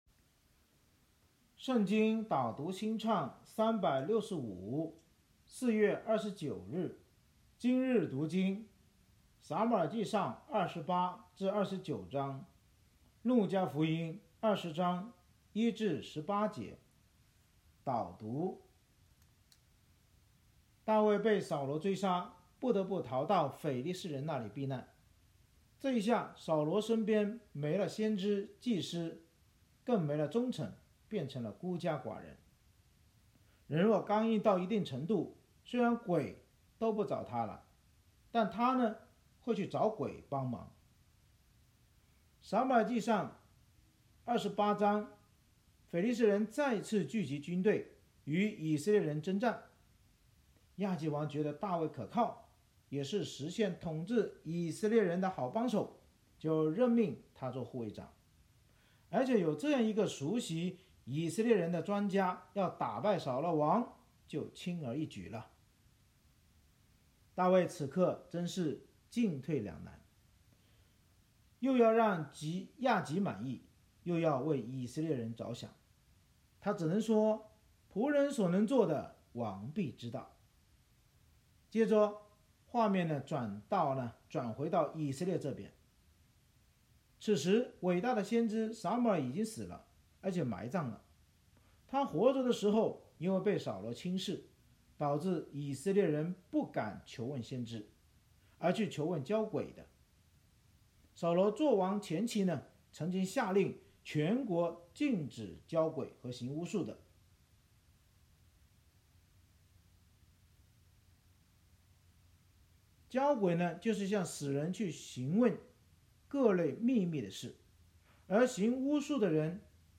圣经导读&经文朗读 – 04月29日（音频+文字+新歌）